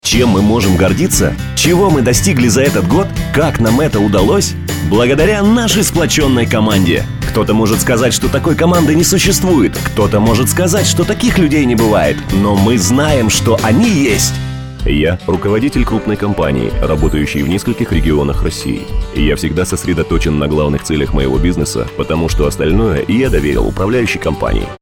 Микрофоны: RODE NTK ; OKTAVA 319 Предусиление: DBX-376 Tube Channel Strip Звуковые интерфейсы: M-Audio ProFire 610, Focusrite Scarlett 2i2 DAW : Logic Pro X